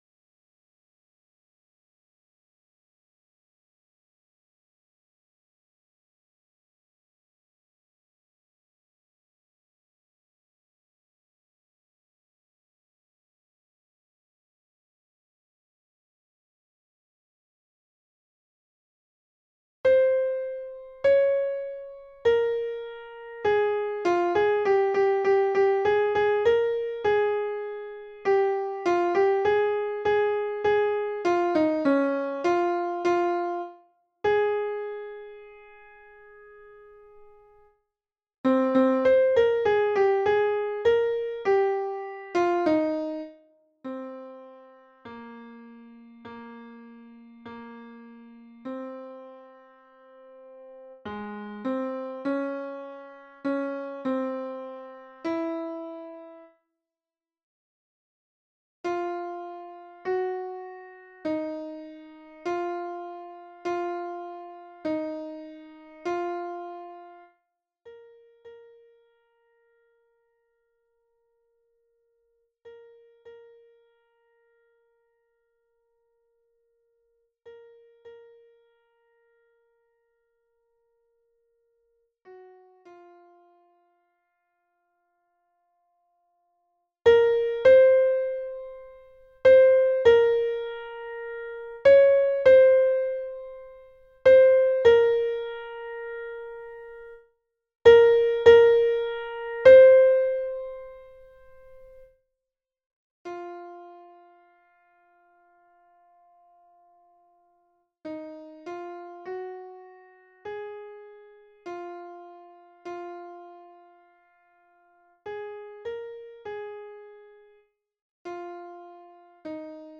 Mezzo Soprano (version piano)